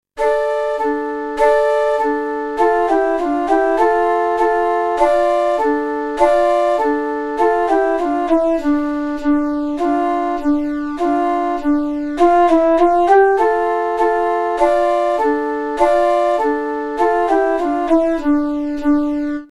Popular de Hungría, flautas a dos voces y xilófonos.